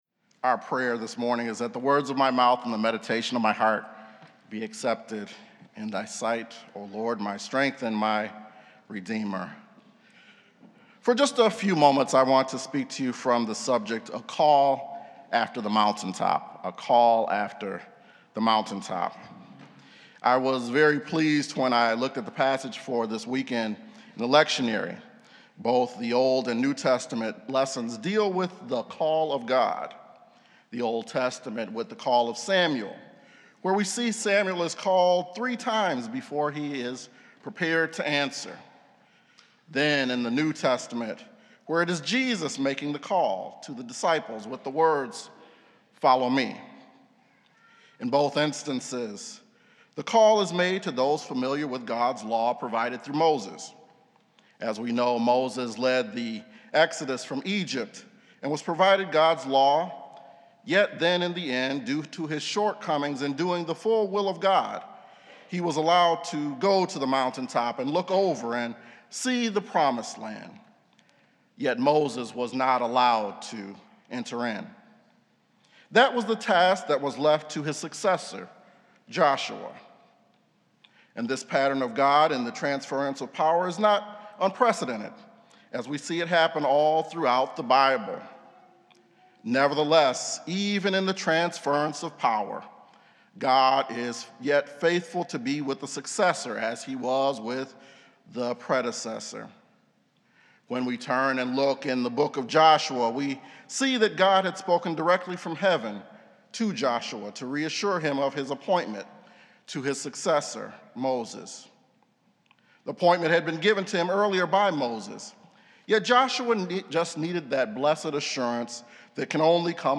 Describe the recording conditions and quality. This is a Spiritus Christi Mass in Rochester, NY.